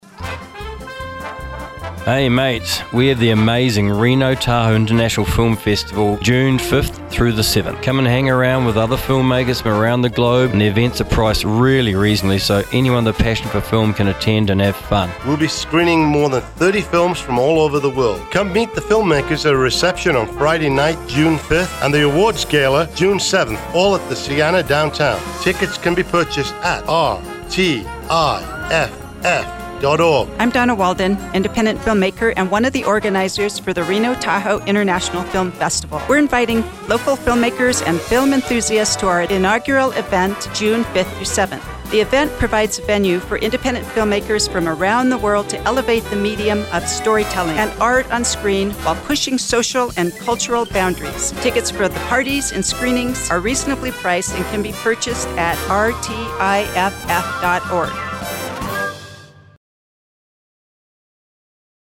Commercial aired on Reno Media Group Radio Stations
FINAL-RENO-TAHOE-FILM-FEST-PSA.mp3